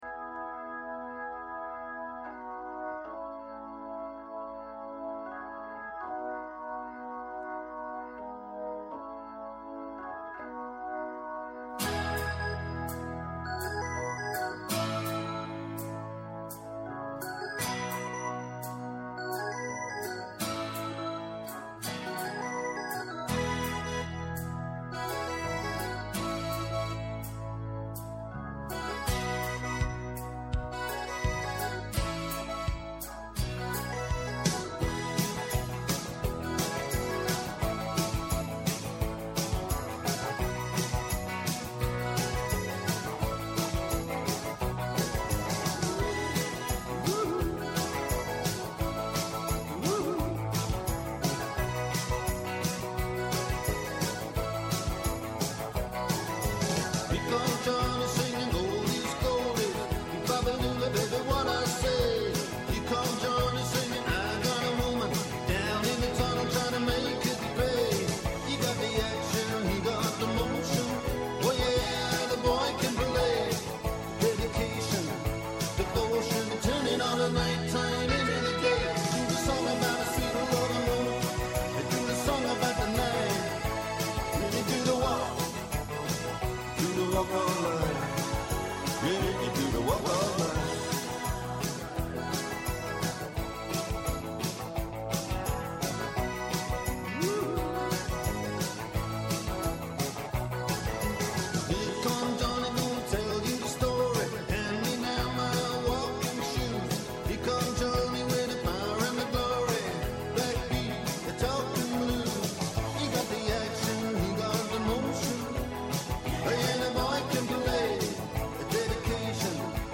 Καλεσμένος σήμερα ο Πέτρος Παππάς, βουλευτής ΣΥΡΙΖΑ.